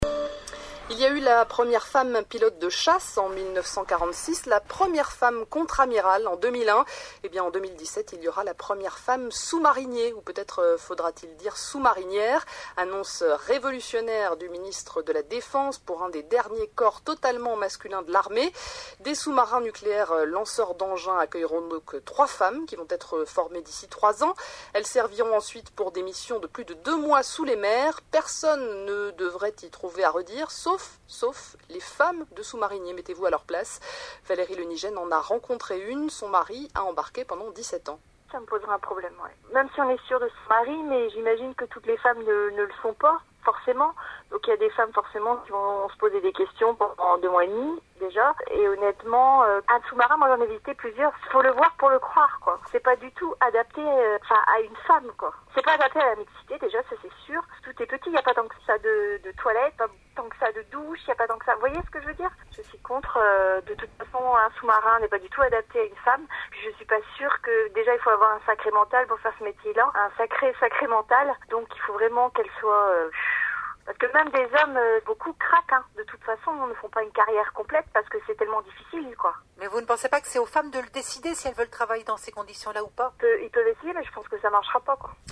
J’ai entendu il y a quelque temps une interview à propos de l’ouverture de la profession de sous-marinier aux femmes dans l’Armée.